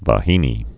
(vä-hēnē, -nā)